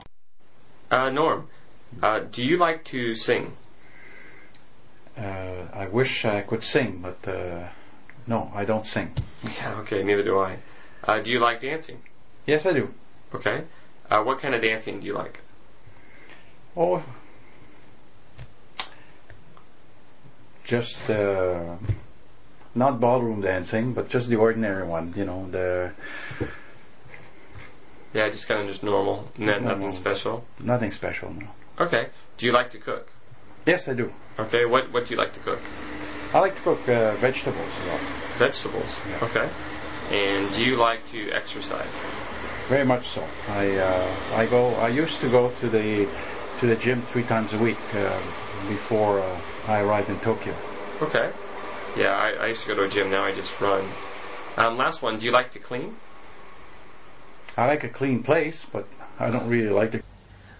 英语高级口语对话正常语速14:喜好(MP3）